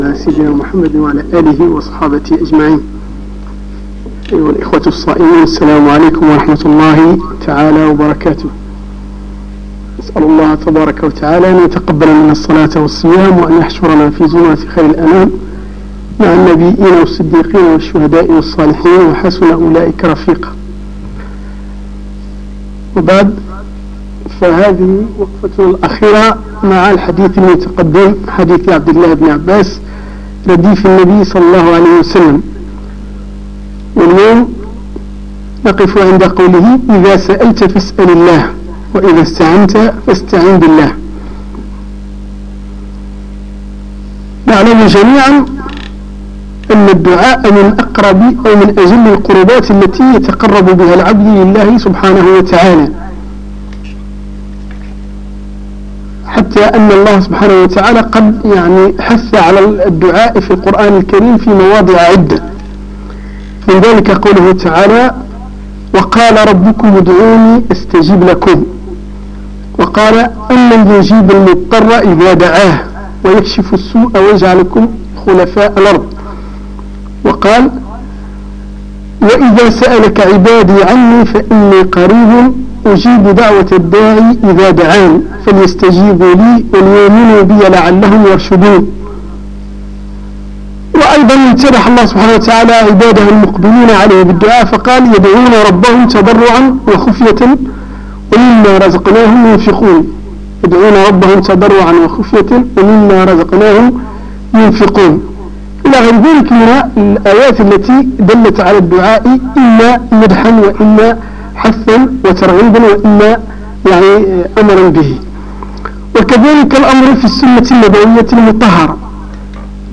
درس ديني
دروس حصرية لموقعنا فلا تتركوها تفوتكم من مسجد ابى بكر الصديق بفقارة الزوى